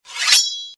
SowrdDraw.mp3